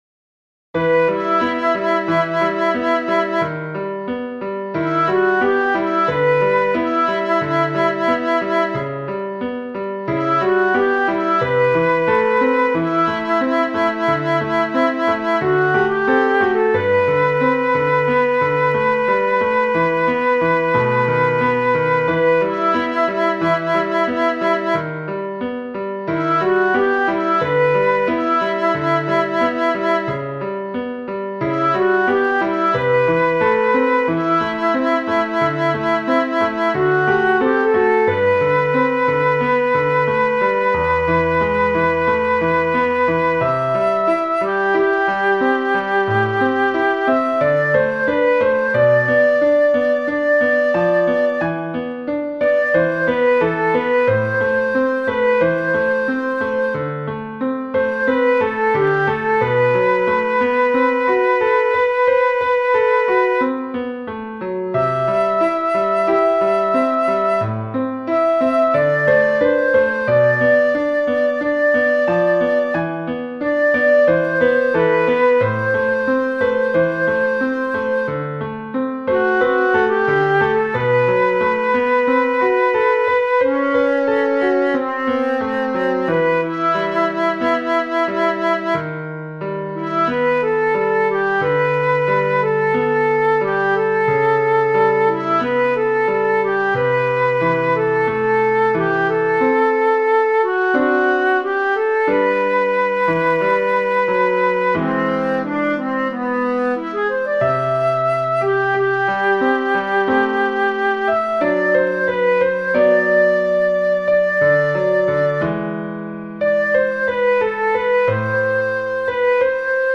Ноты для скрипки, фортепиано.